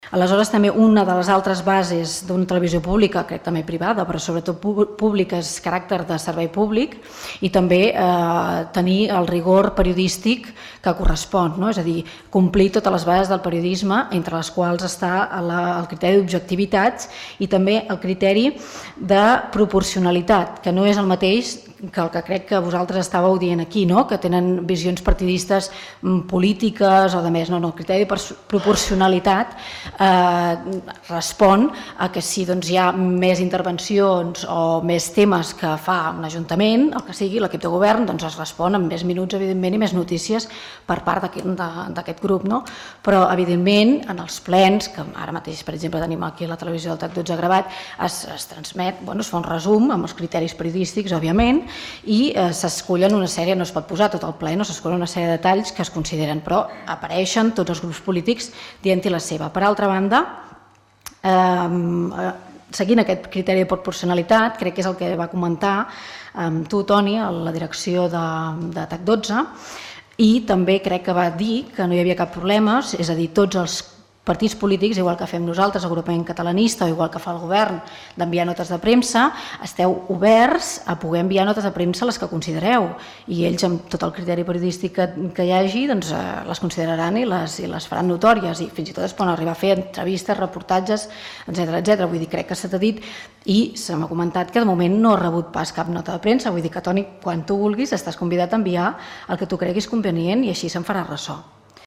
El ple de l’Ajuntament de Montblanc d’aquest dimecres va aprovar renovar el conveni amb TAC12, la televisió pública del Camp de Tarragona.